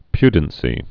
(pydn-sē)